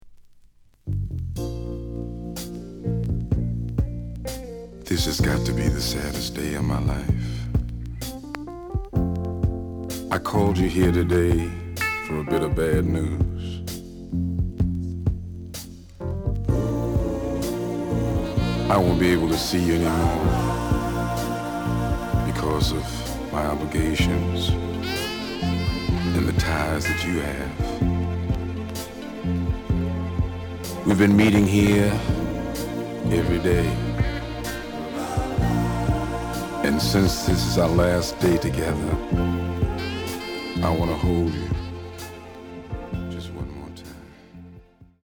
The audio sample is recorded from the actual item.
●Genre: Soul, 70's Soul
Some noise on parts of A side.